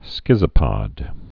(skĭzə-pŏd, skĭtsə-)